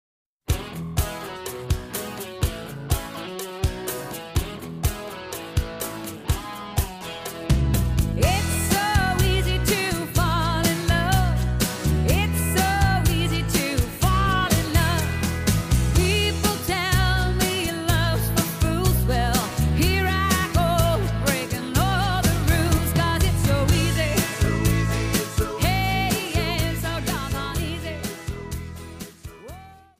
Cha Cha Cha